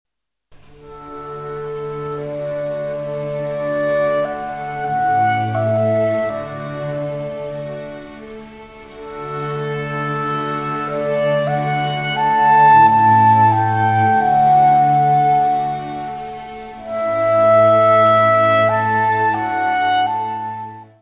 Área:  Música Clássica
Concerto para clarinete = Clarinet concerto K.622
Adagio.